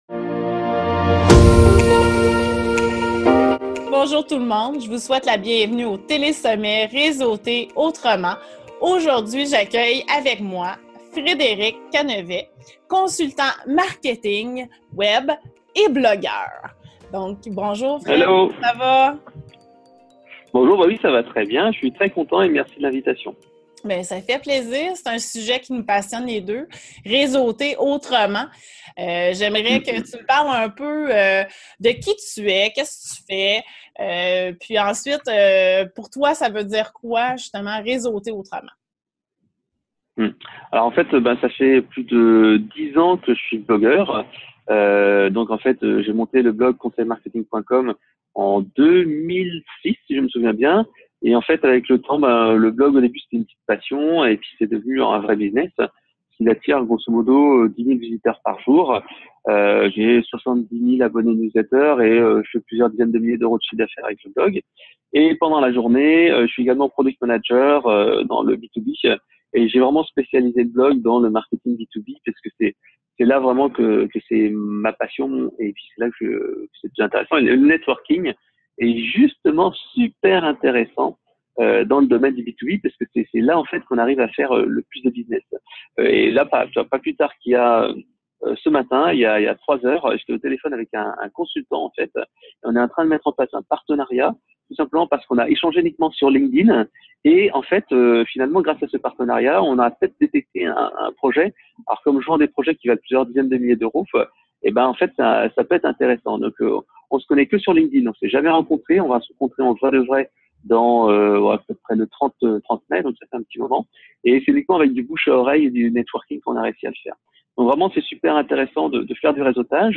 Dans cette longue interview d'une heure, je vous livre mes petits conseils pour trouver des clients via le networking physique ou virtuel.